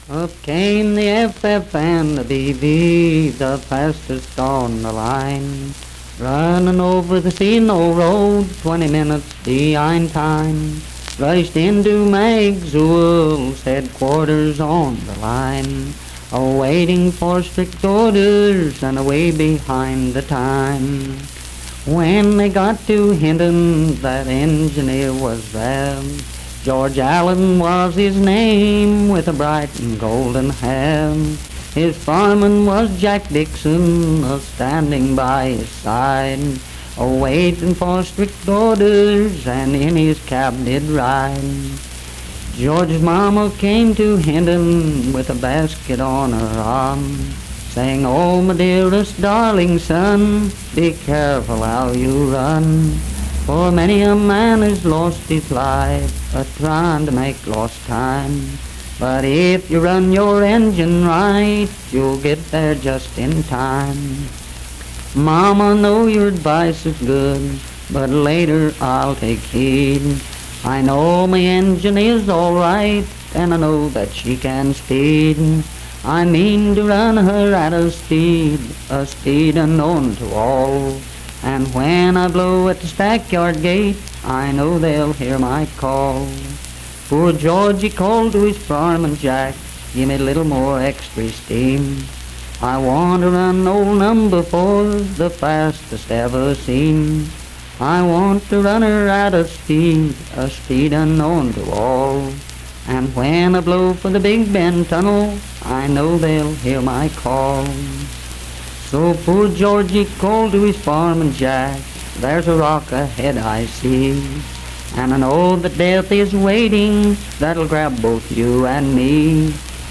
historic field recordings of West Virginia folk music
Unaccompanied vocal music performance
Key Topics: Railroads, Death--Tragedy and Suicide Performance Media: Voice (sung) Rights: Copyright Not Evaluated Location: Wirt County (W. Va.)